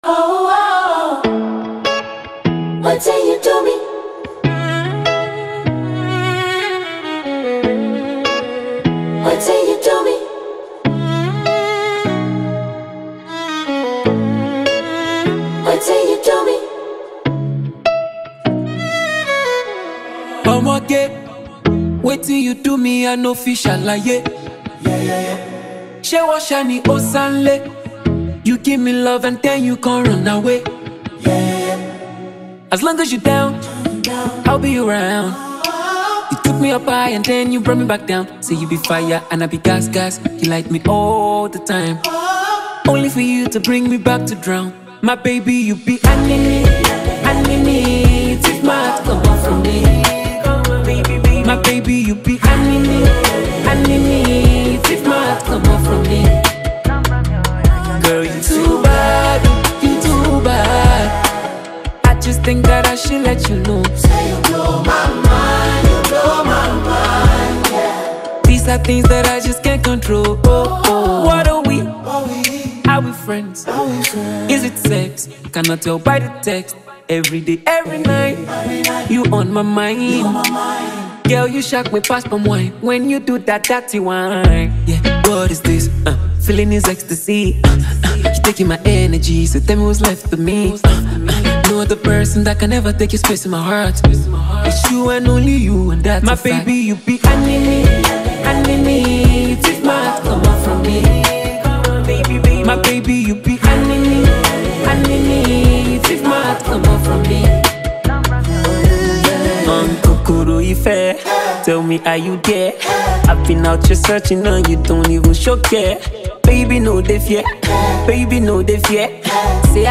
Genre Afrobeats
Nigerian singer-songwriter and performer